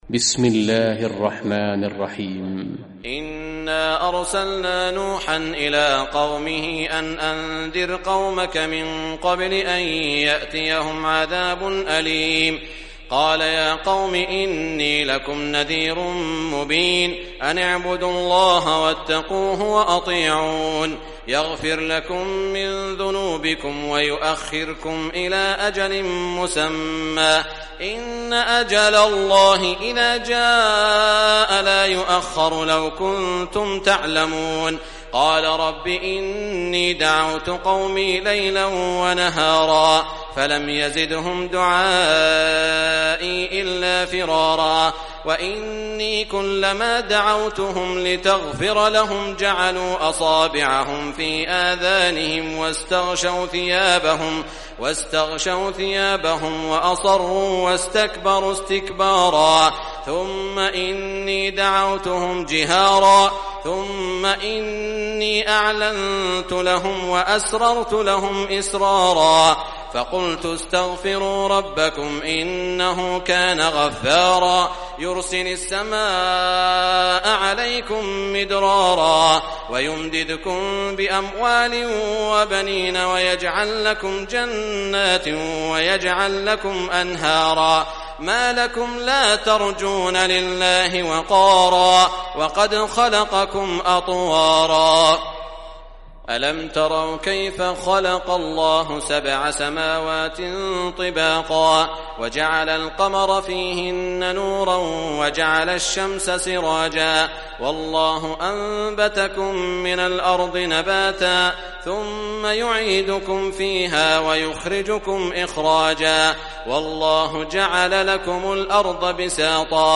Surah Nuh Recitation by Sheikh Shuraim
Surah Nuh, listen or play online mp3 tilawat / recitation in Arabic in the beautiful voice of Sheikh Saud al Shuraim.